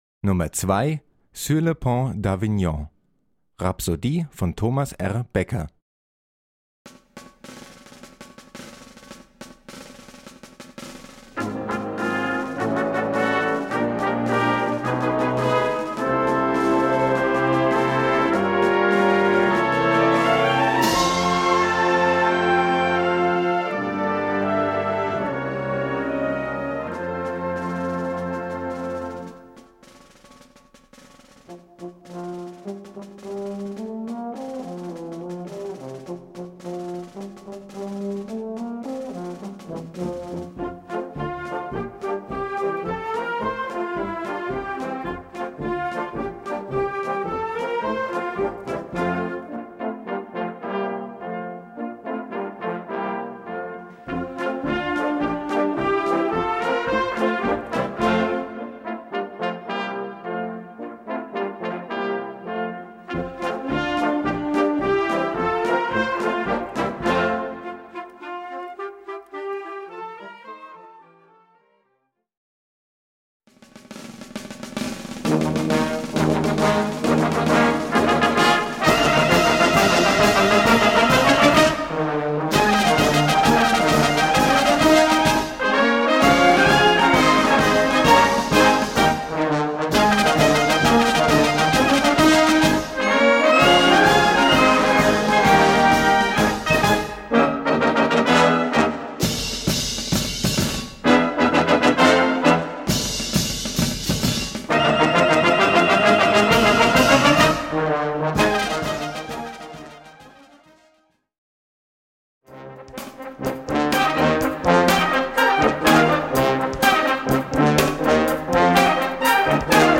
Gattung: Rhapsody
Besetzung: Blasorchester
nach einer französischen Volksweise.